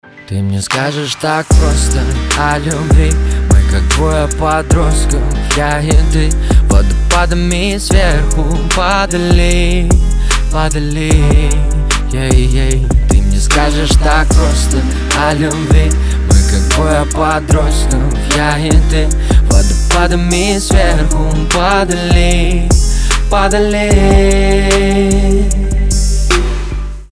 • Качество: 320, Stereo
поп
лирика
Хип-хоп
спокойные